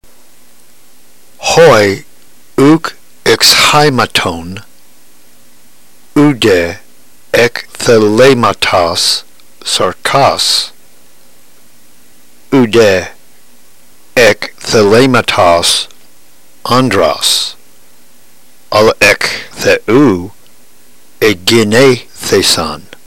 Notice that the words not accented are glided into the following word without hesitation.